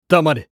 男性
厨二病ボイス～戦闘ボイス～